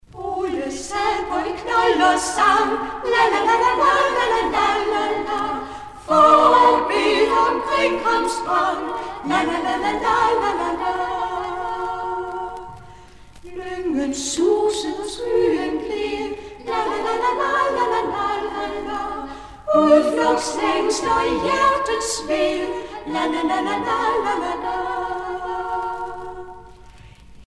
Danske folkesange